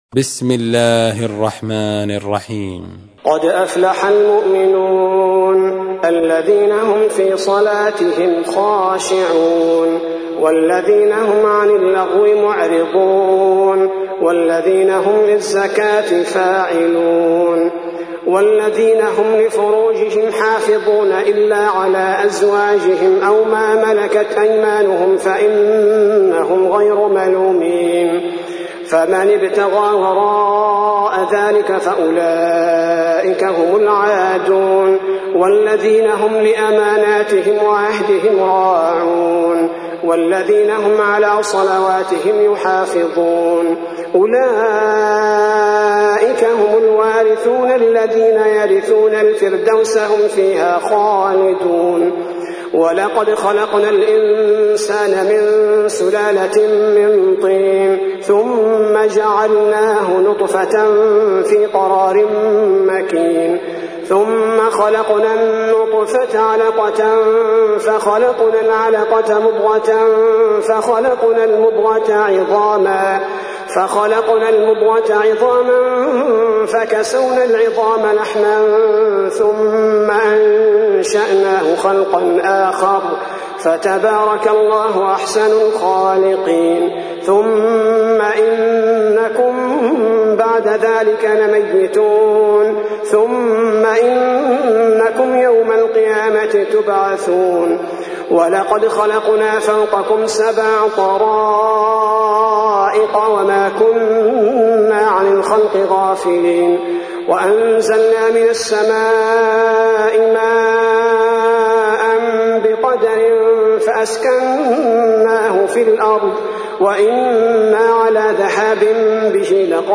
تحميل : 23. سورة المؤمنون / القارئ عبد البارئ الثبيتي / القرآن الكريم / موقع يا حسين